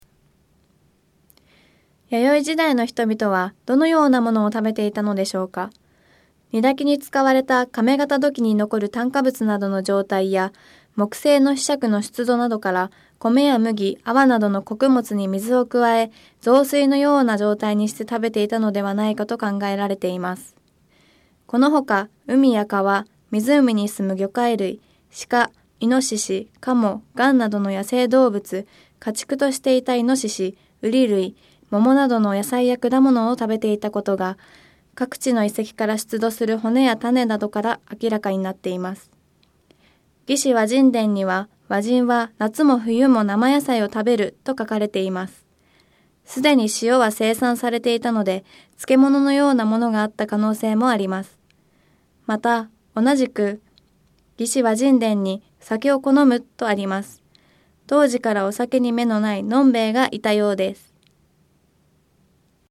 音声ガイド 前のページ 次のページ ケータイガイドトップへ (C)YOSHINOGARI HISTORICAL PARK